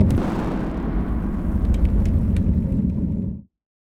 fireball-explosion-01.ogg